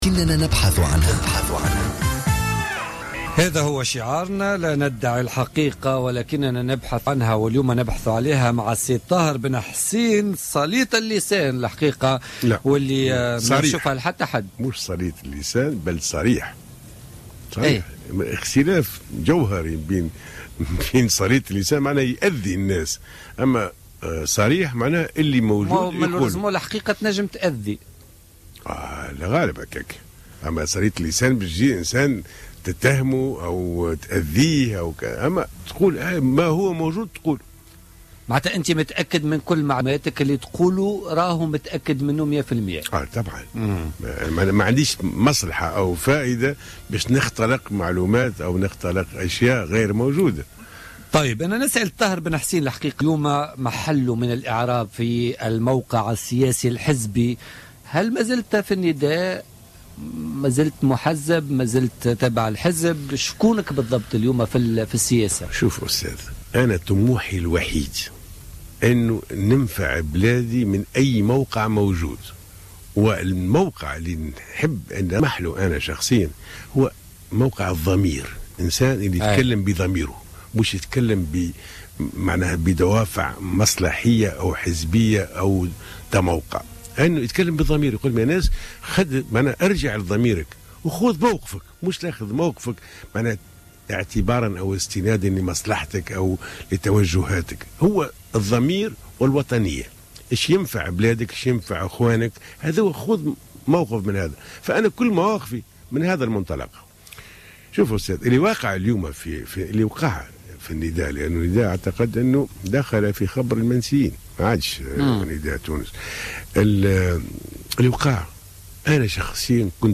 قال الناشط السياسي الطاهر بن حسين عضو المكتب التنفيذي لنداء تونس سابقا ضيف بوليتيكا اليوم الأربعاء 17 فيفري 2016 إن نداء تونس دخل في خبر المنسيين مضيفا أنه كان يتوقع ماحدث في نداء تونس منذ سبتمبر 2013 وحاول تفاديه ولكنه استقال بعد أن تيقن أن الخط لن يتغير داخل نداء تونس .